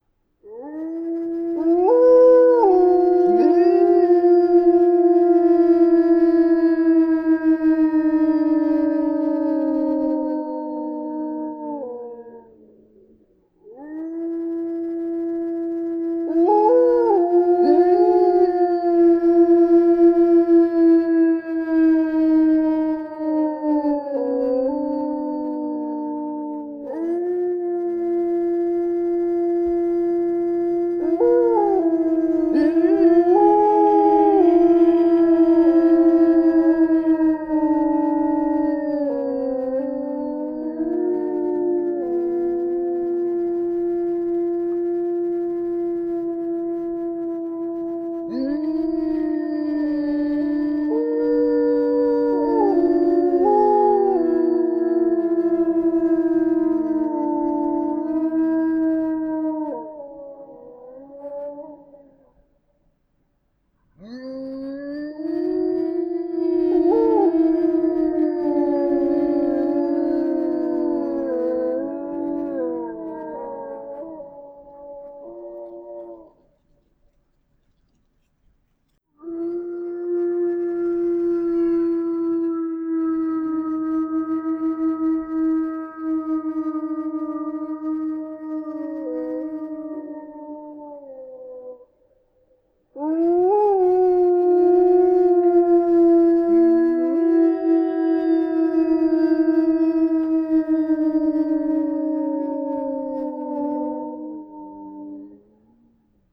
Index of /sound_effects_and_sound_reinforcement/The_Passion_of_Dracula/sounds
E01_86b_wolves.wav